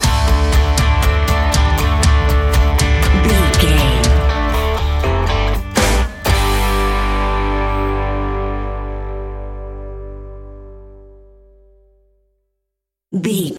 Ionian/Major
electric guitar
drums
bass guitar
country rock